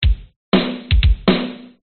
snare x5
描述：short synthesized snare drum sample
标签： drum sample short snare synth
声道立体声